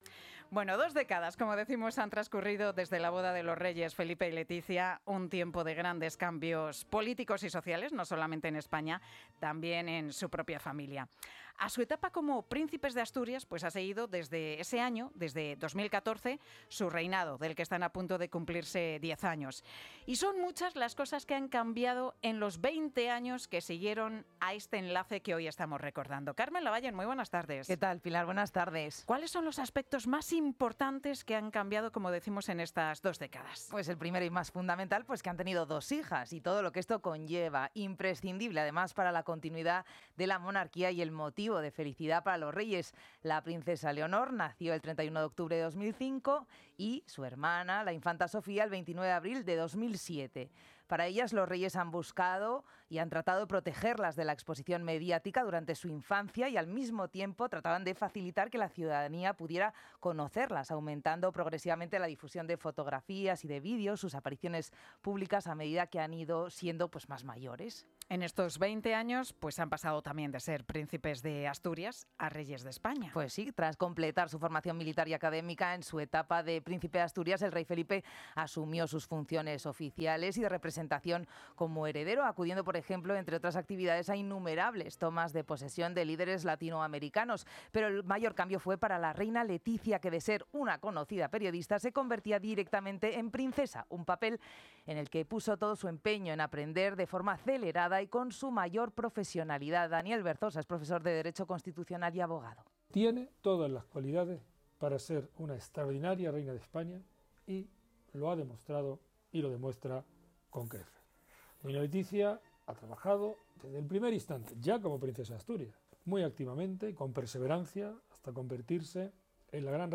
Me entrevistaron en COPE.